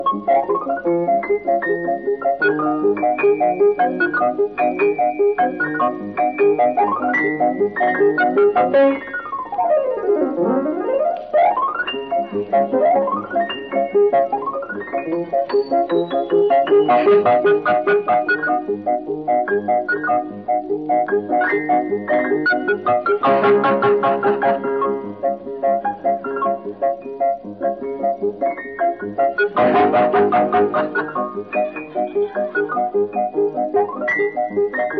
# Vocal